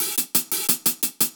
Index of /musicradar/ultimate-hihat-samples/175bpm
UHH_AcoustiHatA_175-02.wav